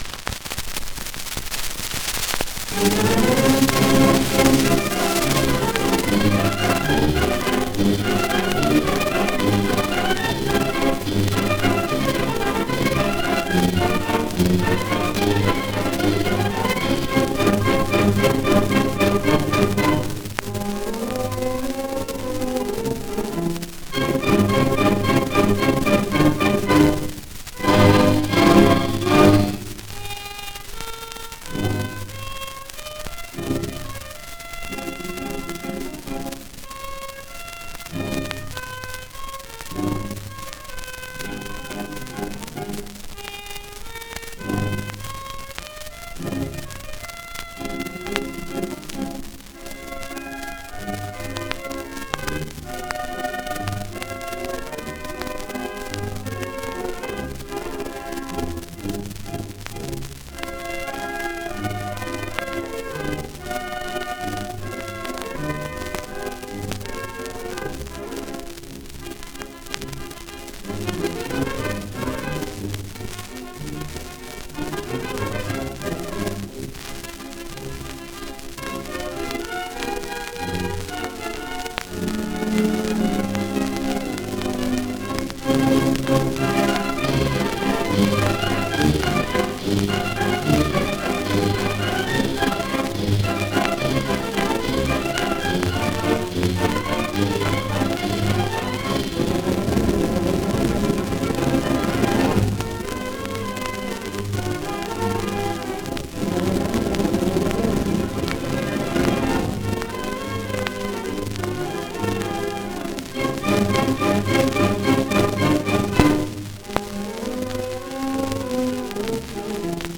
La alegría de la huerta : jota